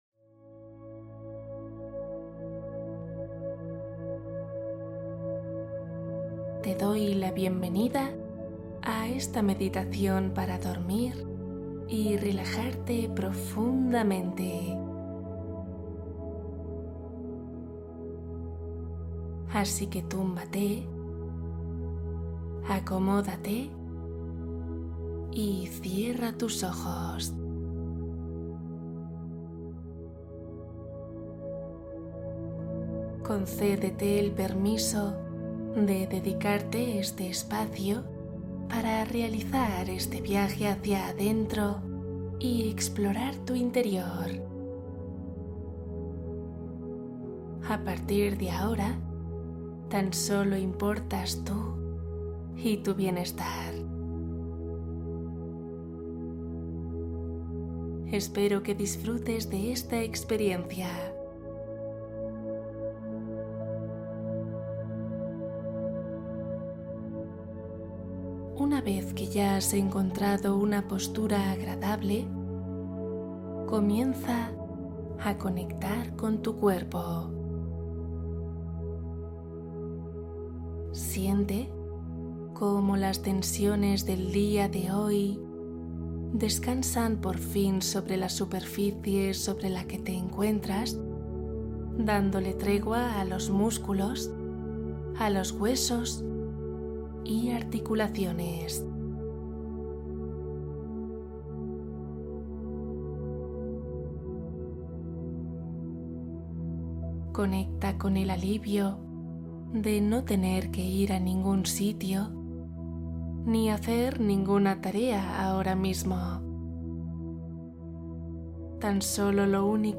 Mañanas conscientes: meditación para iniciar con claridad